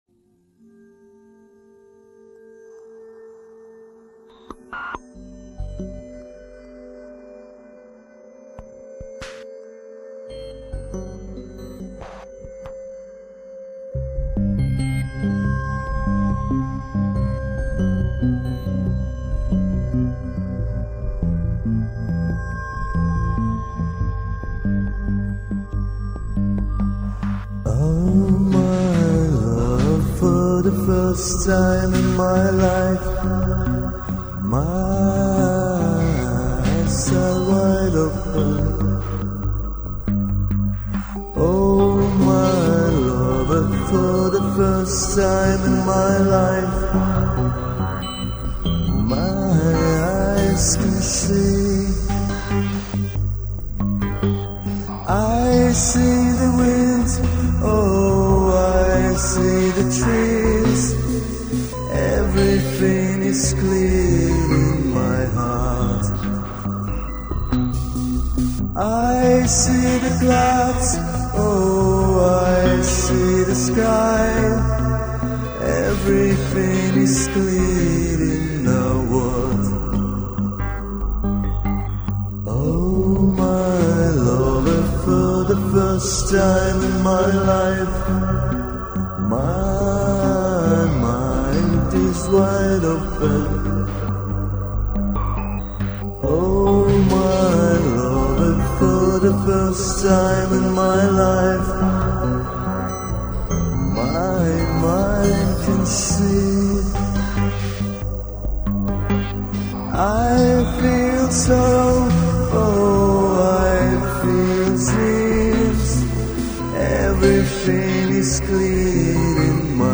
Прямо затягивает,манит композиция.